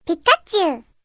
Pikachu! (a really cute sound)